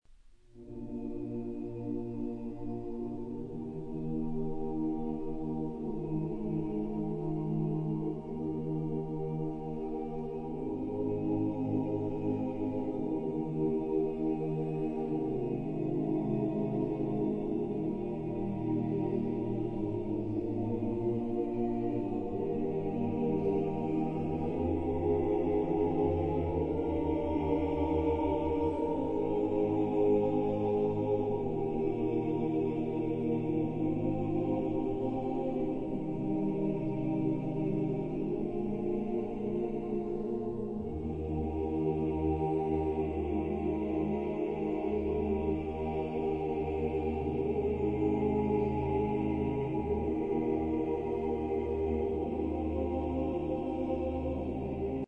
Початок » CDs» Релігійна Мій аккаунт  |  Кошик  |  Замовити